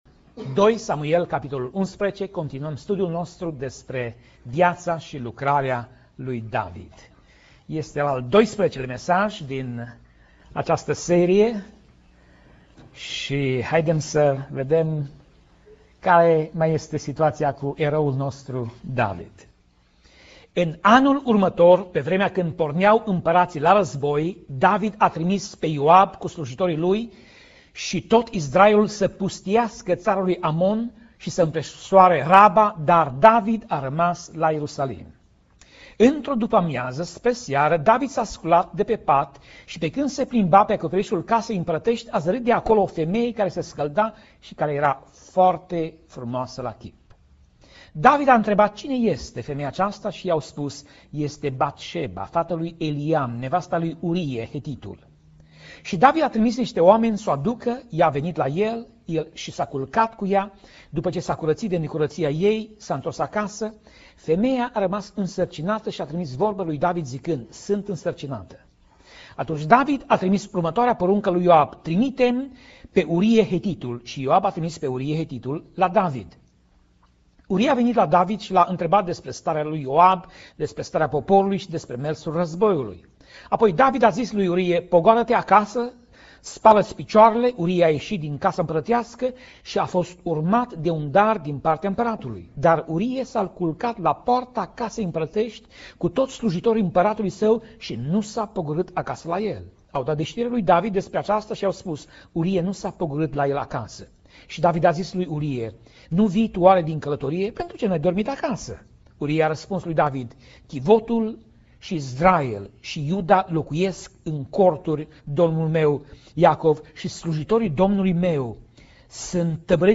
Pasaj Biblie: 2 Samuel 11:1 - 2 Samuel 11:27 Tip Mesaj: Predica